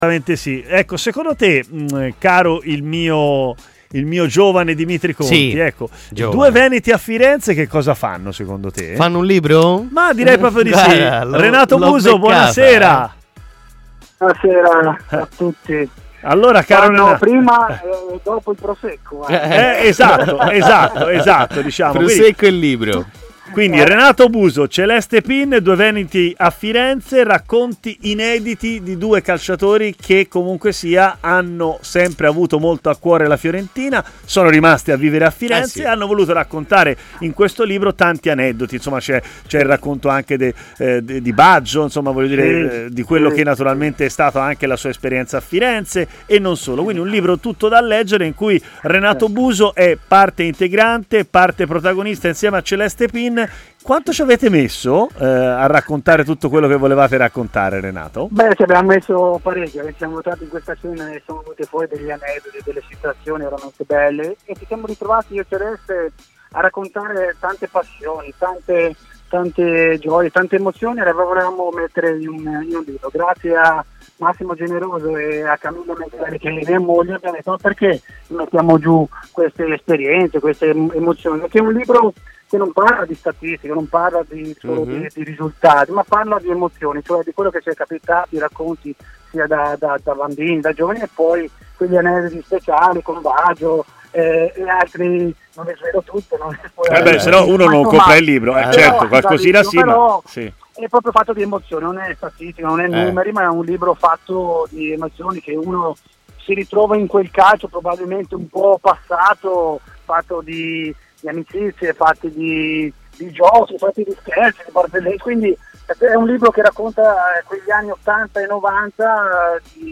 Renato Buso è stato ospite a Stadio Aperto, trasmissione pomeridiana di TMW Radio, dove si è soffermato su diversi temi, a cominciare dal libro "Due veneti a Firenze", incentrato sul passato viola in compagnia di Celeste Pin: "Ci sono aneddoti molto belli, passioni vissute con Celeste, in un libro che parla non solo di risultati, ma anche di emozioni".